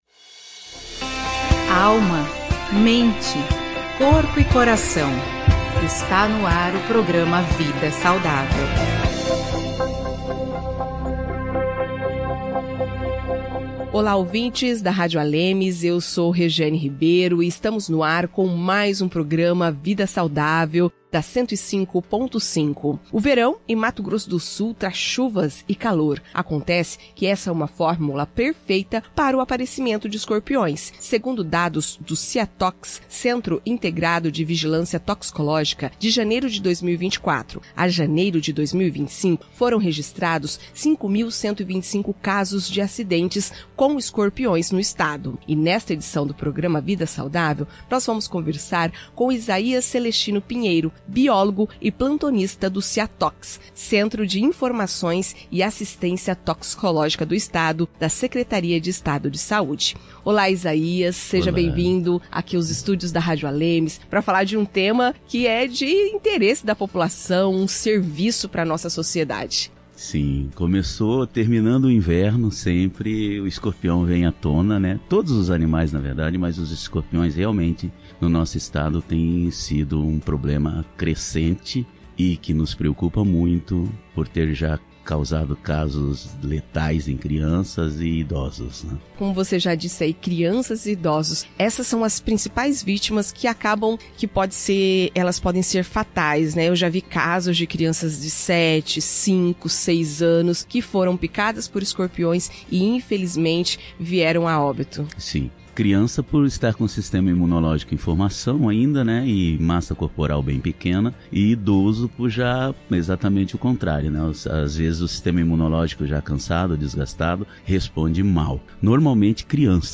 Com a chegada do verão em Mato Grosso do Sul, o aumento das chuvas e das temperaturas cria o ambiente ideal para a proliferação de escorpiões. Para entender melhor os riscos e as formas de prevenção, o Programa Vida Saudável, da Rádio ALEMS, entrevista o biólogo